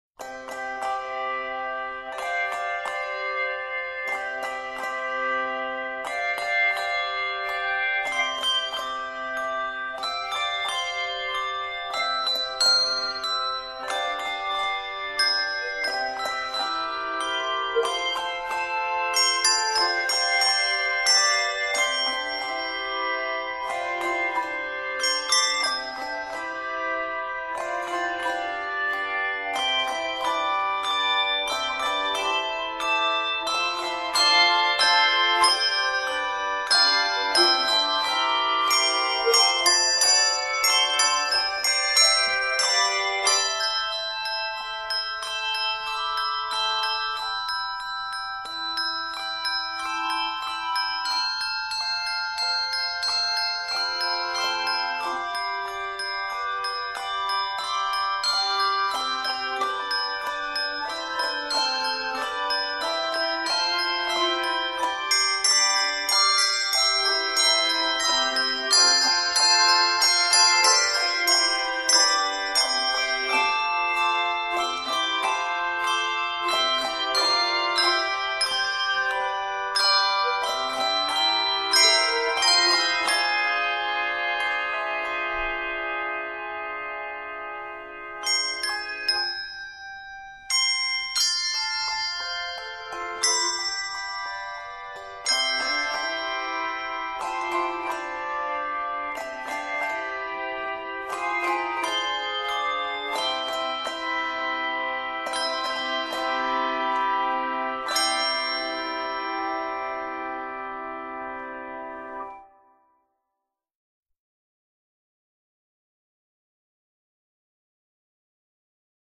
two or three octaves of handbells or handchimes